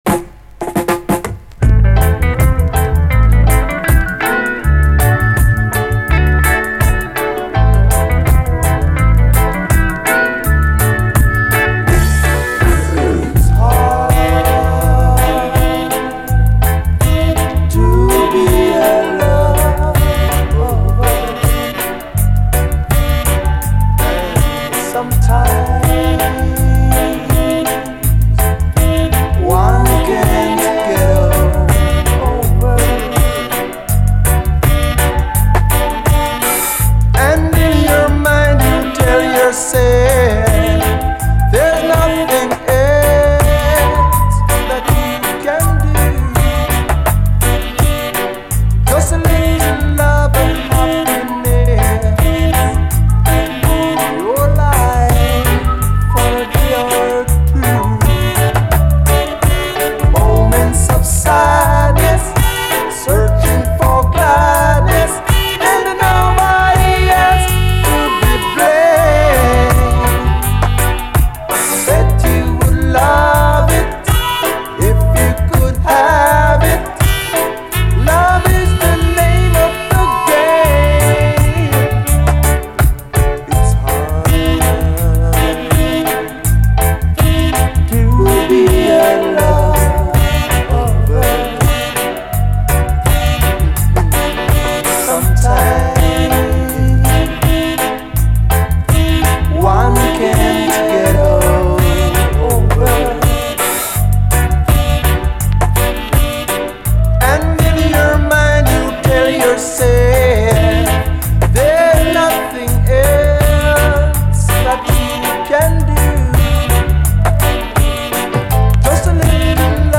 REGGAE
両面最高な哀愁ラヴァーズ！
間奏のピアノ＆サックス・パートも泣かせる。
哀愁たっぷりですが繊細さと洗練度もハイレベル。